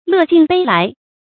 樂盡汞來 注音： ㄌㄜˋ ㄐㄧㄣˋ ㄅㄟ ㄌㄞˊ 讀音讀法： 意思解釋： 猶言樂極生悲。